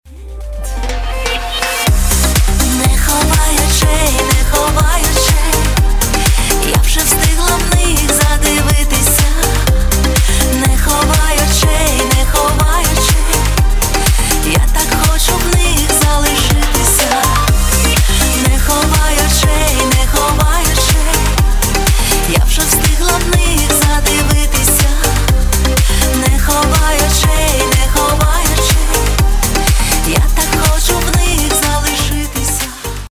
• Качество: 320, Stereo
поп
женский вокал
романтичные